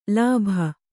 ♪ lābha